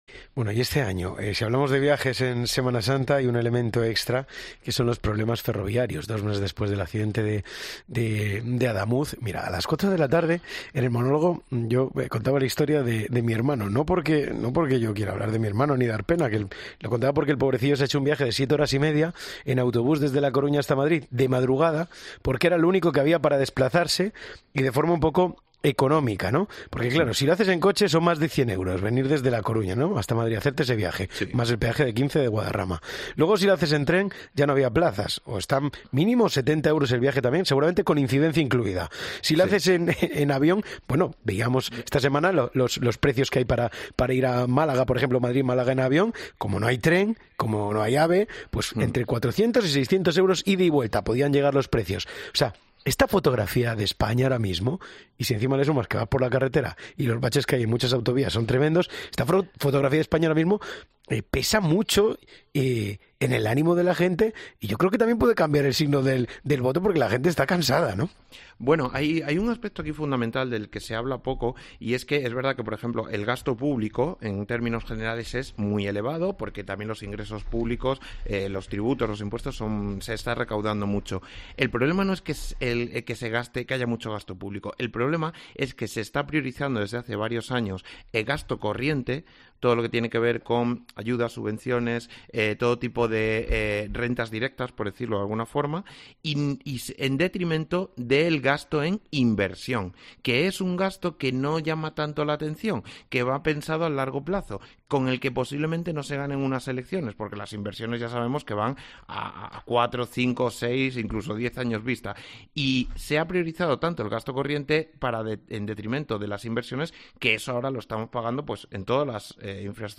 Periodista experto en economía
“130 euros me costó llenarla, y hace un mes, con 90 o 100, lo llenabas”, lamentaba un transportista en el programa, mientras otros ciudadanos califican los precios de “locura”.